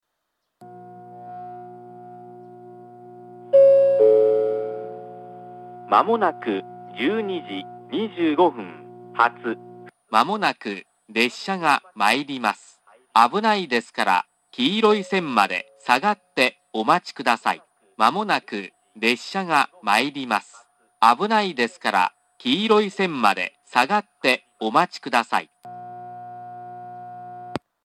発車ベルはありませんが、接近放送があります。
１番線下り接近放送 接近予告放送が流れている途中から流れ始めています。